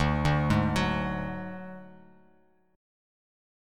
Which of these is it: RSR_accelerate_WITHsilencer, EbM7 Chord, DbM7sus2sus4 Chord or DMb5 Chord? DMb5 Chord